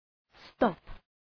Προφορά
{stɒp}